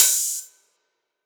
OpenHH SwaggedOut 2.wav